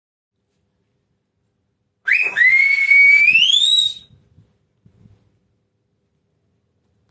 Així, si un xiulador diu al seu interlocutor “Quiero que mañana vengas a mi casa a las cuatro de la tarde, és possible que aquest, que ha cregut entendre el missatge, li pregunti: “¿Que si quieres que mañana yo vaya a tu casa a las cuatro de la tarde?, i a això l’emissor respondrà utilitzant una partícula molt especial, una expressió, pel que sembla d’origen prehispànic, que sona com a “ejey